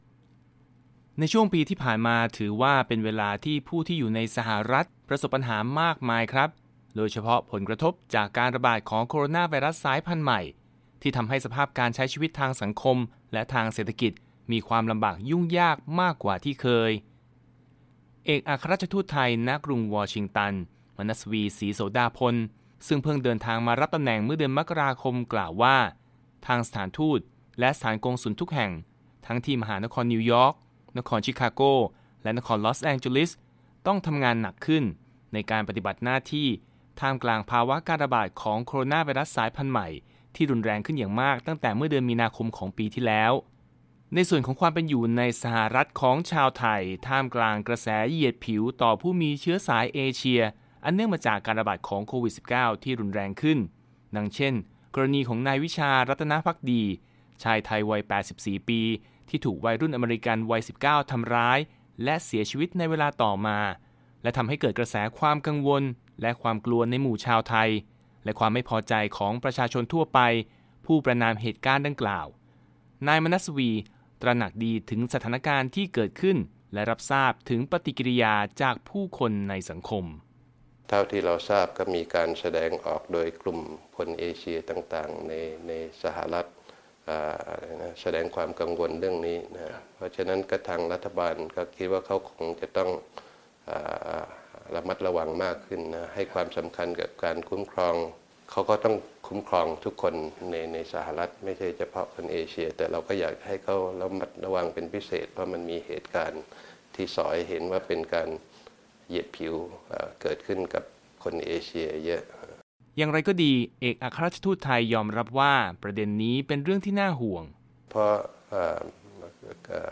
Interview With Amb Manasvi